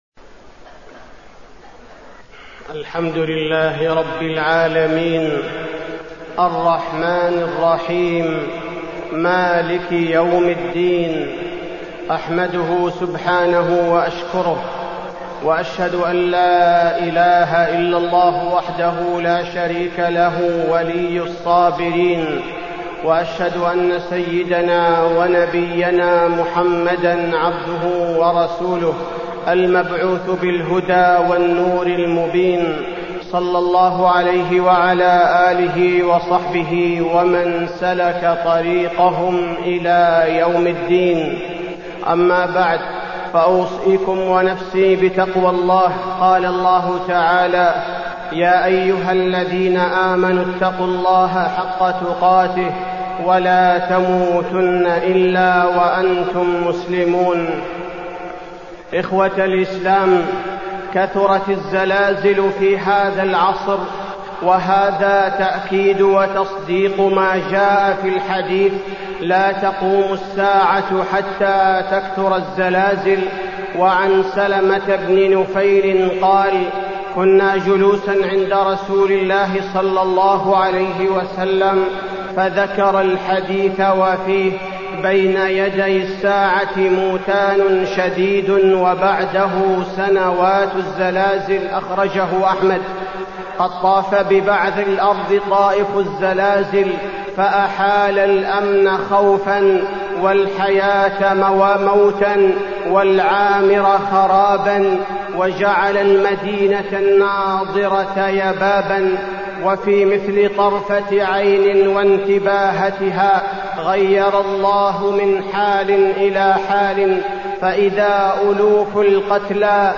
تاريخ النشر ١٩ ذو القعدة ١٤٢٥ هـ المكان: المسجد النبوي الشيخ: فضيلة الشيخ عبدالباري الثبيتي فضيلة الشيخ عبدالباري الثبيتي أحداث الزلازل الأخيرة